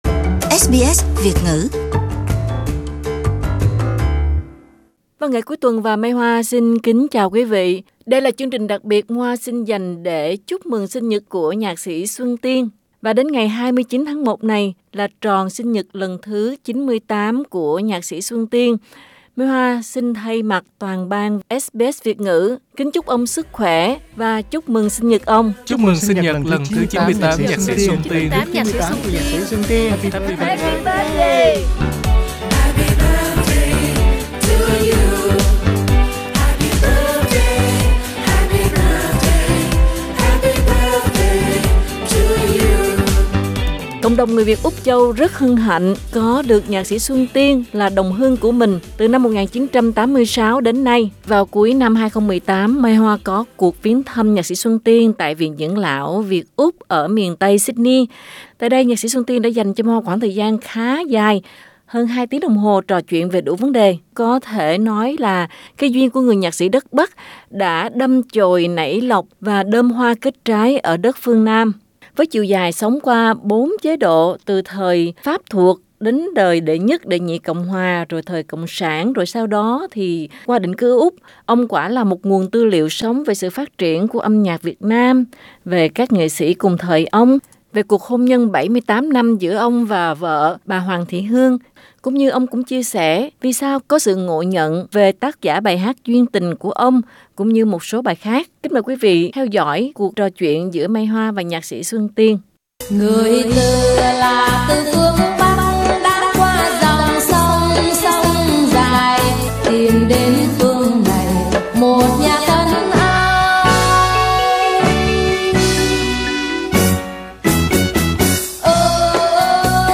Vào cuối năm 2018, SBS Việt Ngữ có cuộc gặp gỡ nhạc sĩ Xuân Tiên tại Nhà Dưỡng Lão Việt Úc tại miền Tây Sydney. Tại đây ông đã dành cho SBS Việt Ngữ một buổi trò chuyện khá cởi mở.